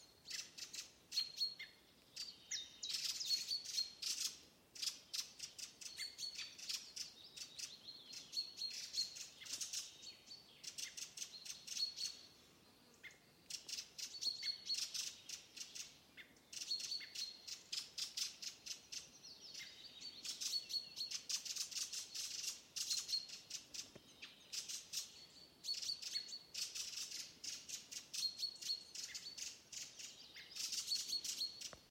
Birds -> Thrushes ->
Fieldfare, Turdus pilaris
StatusSpecies observed in breeding season in possible nesting habitat